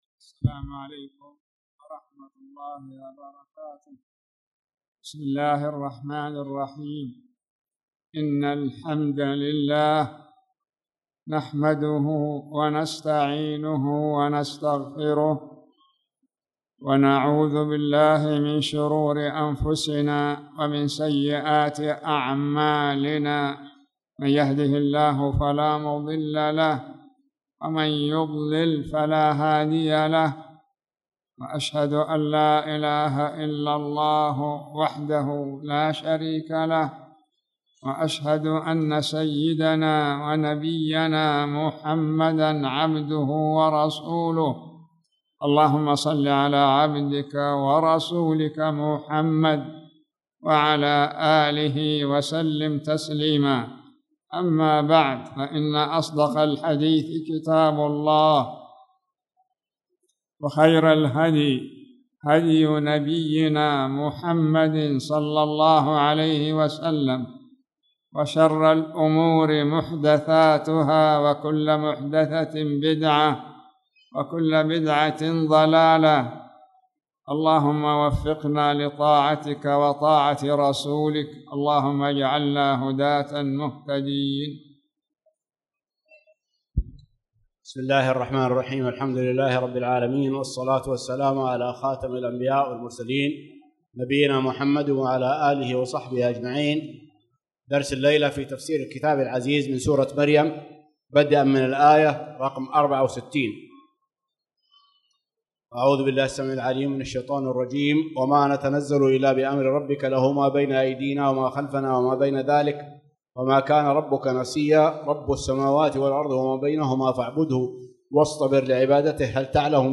تاريخ النشر ٢٠ ربيع الثاني ١٤٣٨ هـ المكان: المسجد الحرام الشيخ